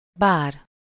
Ääntäminen
British: IPA : /bɑː/ US : IPA : [bɑː]